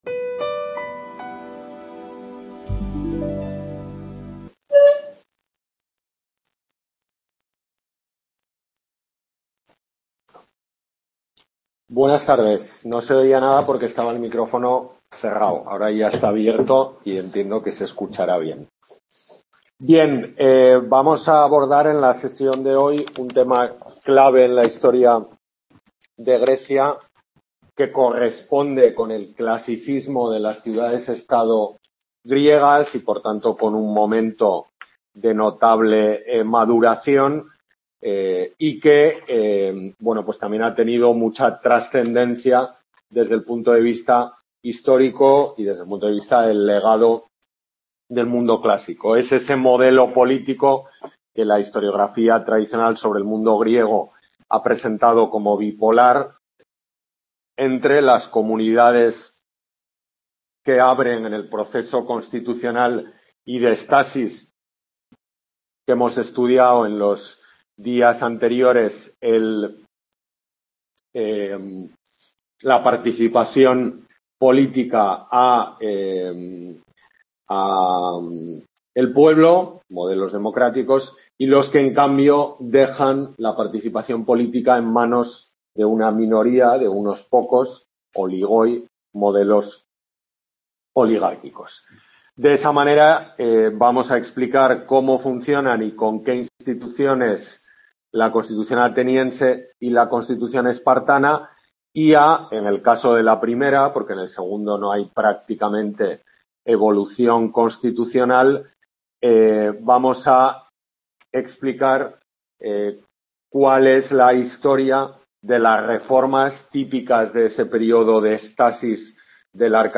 Tutoría de Historia Antigua del Grado de Arte, UNED Pamplona